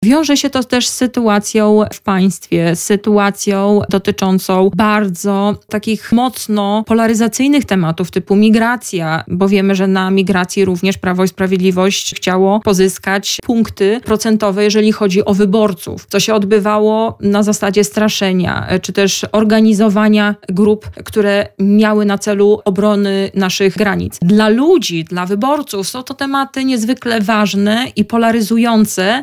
Ten sondaż komentowała na naszej antenie posłanka Platformy Obywatelskiej Małgorzata Gromadzka, która była gościem Radia Lublin: